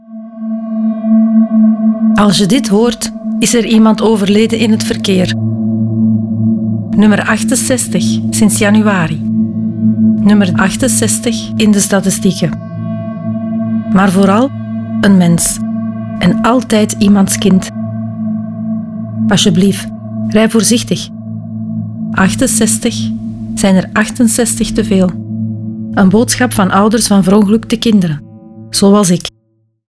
Ouders van Verongelukte Kinderen maken een radiospot voor elk dodelijk verkeersslachtoffer.
De spots werden geproduced door Raygun met de medewerking van échte ouders van verongelukte kinderen, geen acteurs.